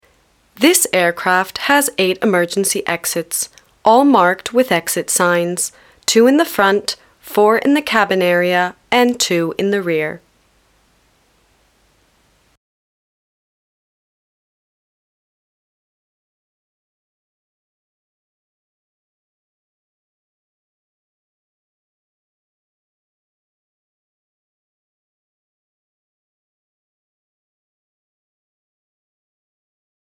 Bandes-son
Plane announcement
- Basse
American-Standard (native)